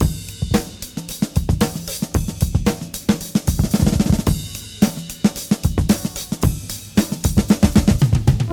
113 Bpm Breakbeat E Key.wav
Free breakbeat - kick tuned to the E note. Loudest frequency: 1819Hz
113-bpm-breakbeat-e-key-4fS.ogg